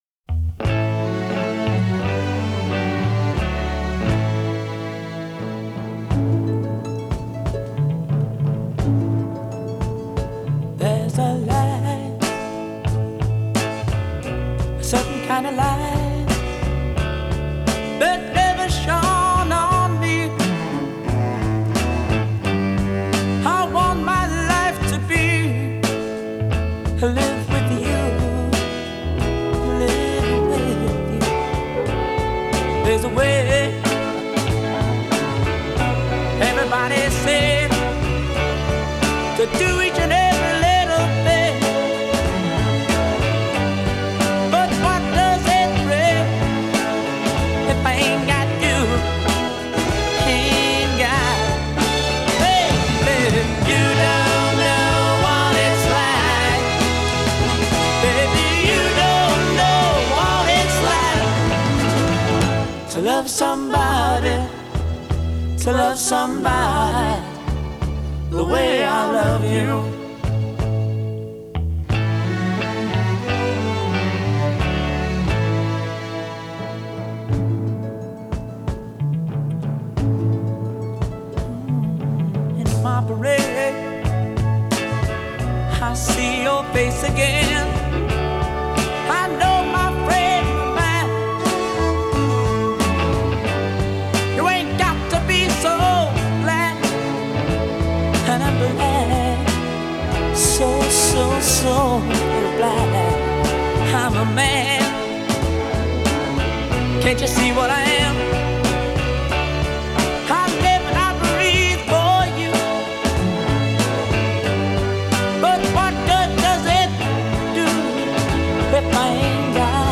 Genre: Pop, Pop Rock, Disco